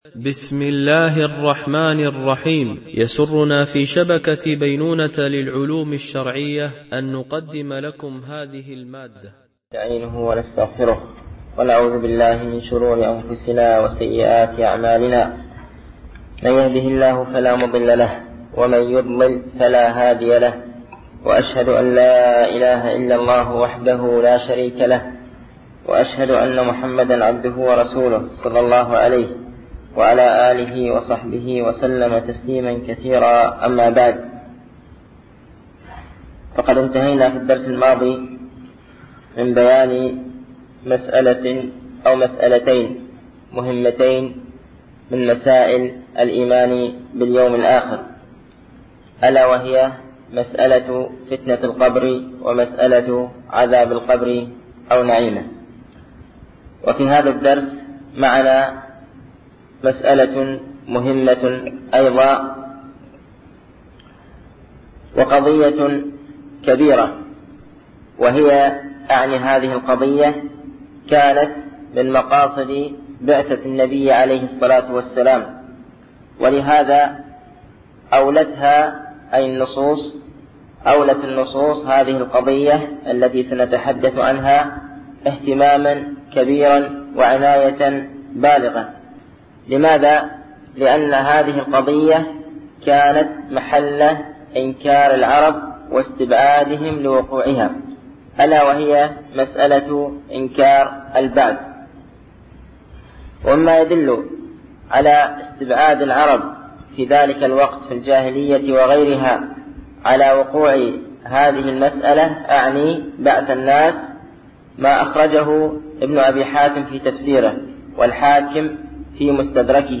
الألبوم: شبكة بينونة للعلوم الشرعية التتبع: 40 المدة: 38:33 دقائق (8.86 م.بايت) التنسيق: MP3 Mono 22kHz 32Kbps (CBR)